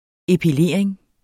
Udtale [ epiˈleˀɐ̯eŋ ]